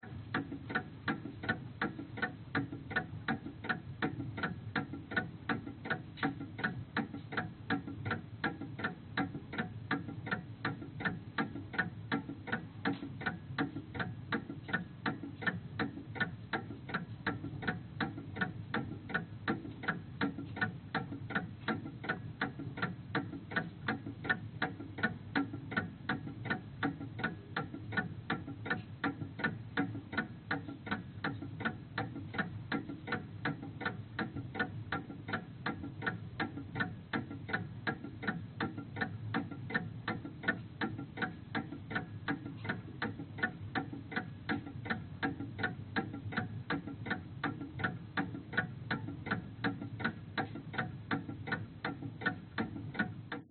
挂钟鸣响
描述：一个挂钟鸣响9次，10次和11次。 包括滴答的噪音。 时钟已经老了，所以也许钟声并不是那么完美，而不是新的。
Tag: 时间 时钟 滴答 滴答滴答 金属 古董 挂钟